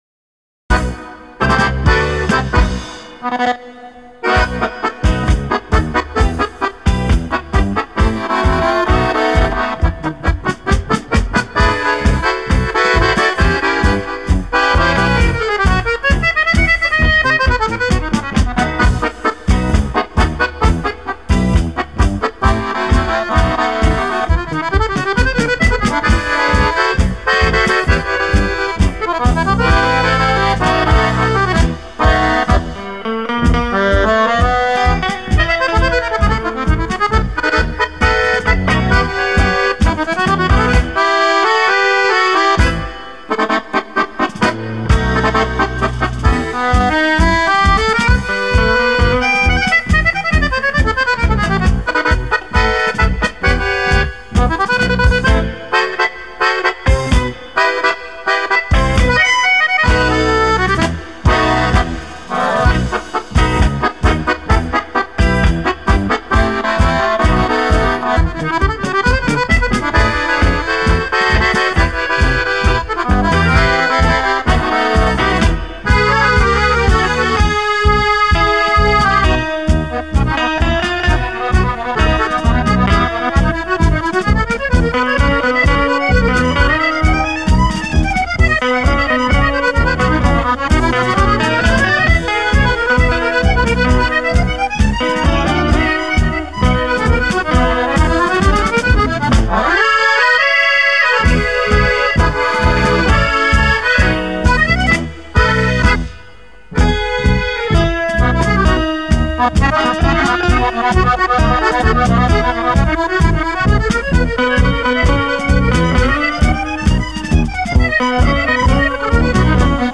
удивительное танго